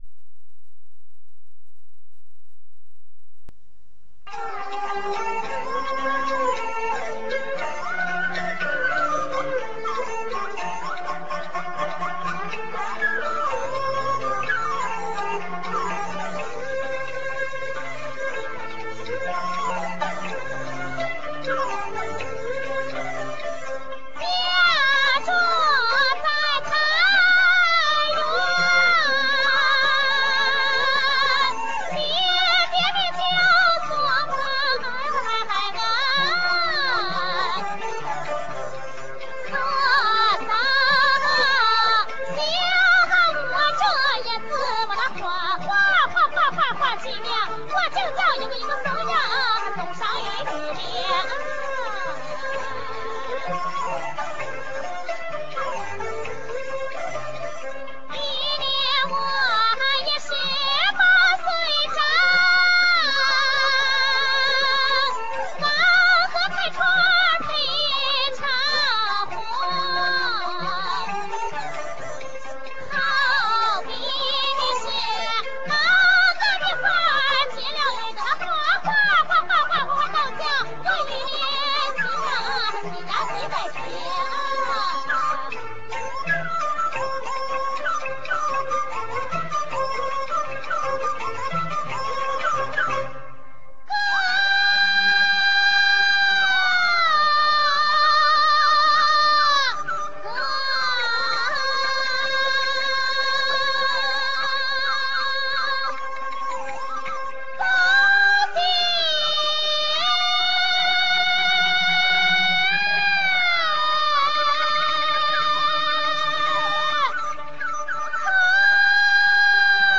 在表现形式上，主要以二个人表演为主，但也有多人表演的二人台，二人台的唱腔婉转、多变、高亢。